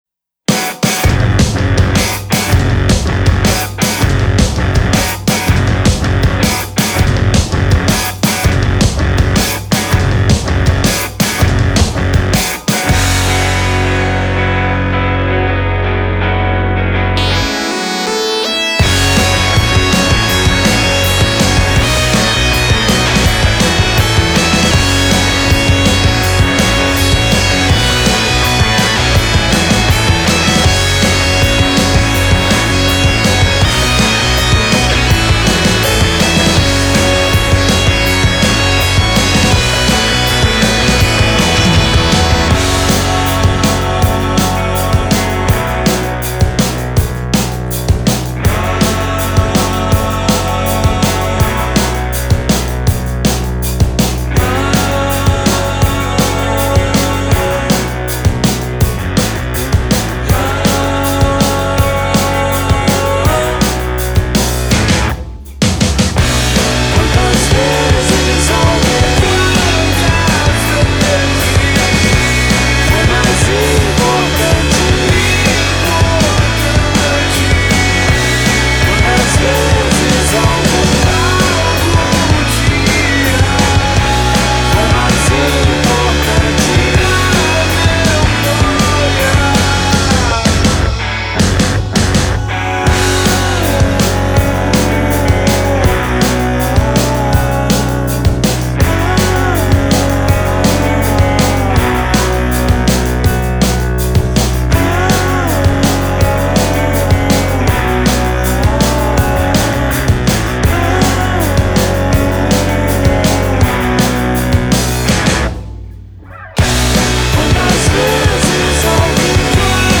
modern synthesized sounds and valuing dynamic